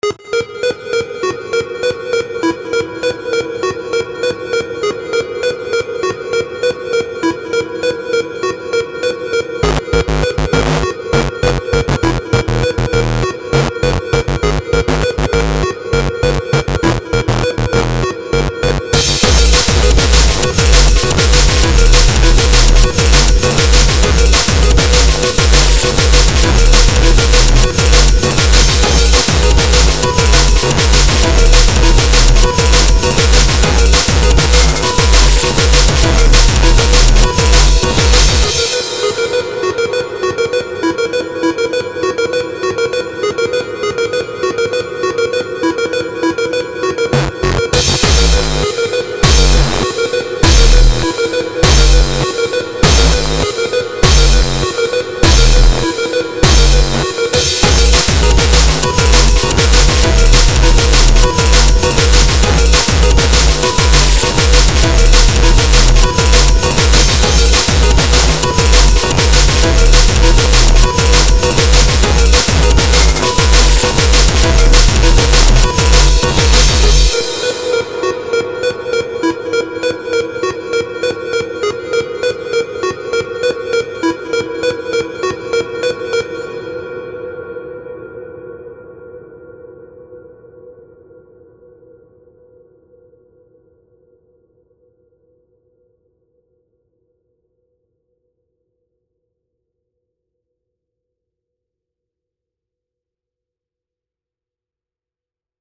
difficult [chipbreak/breakcore]
200bpm electronic edm rave hardcore breakcore drillnbass breaks